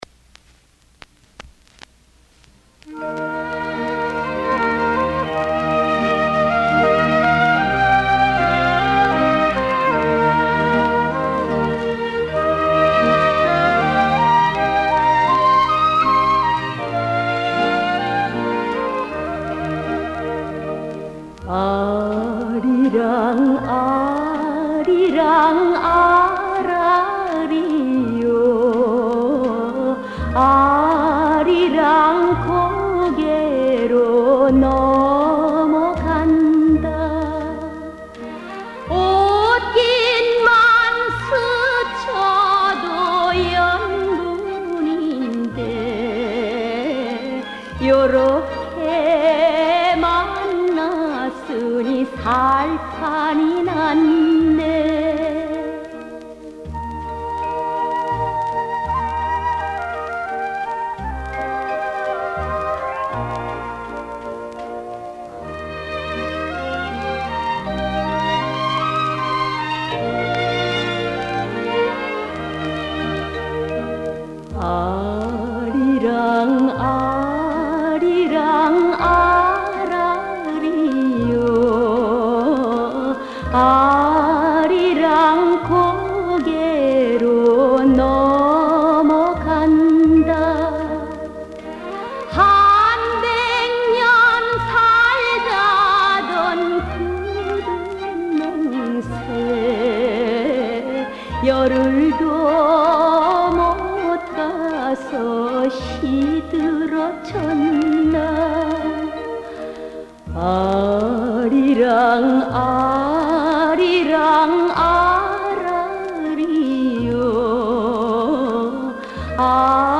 Anytime as you are reading along, you may click on the speaker and, if you have proper machinery, hear the prime Korean folk song - "Arirang" - sung by an unknown lady soloist with a fine voice.